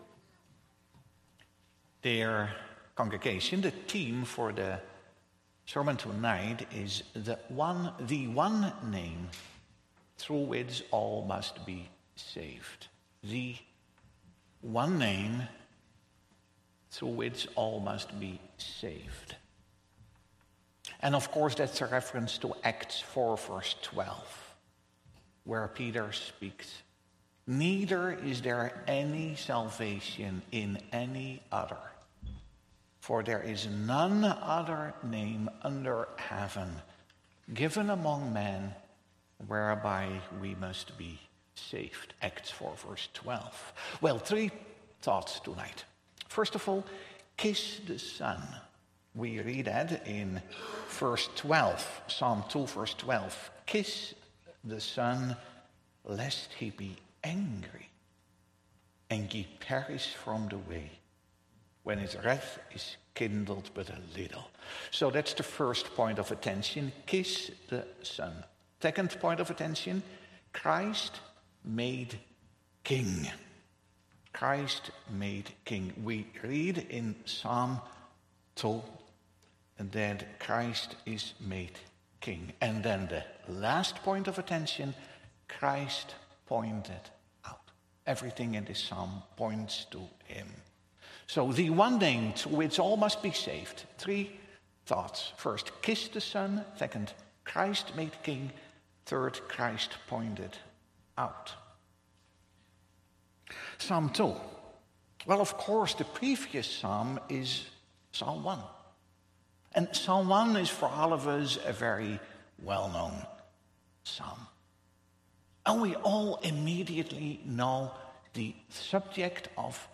Sermon Audio | Providence Reformed Church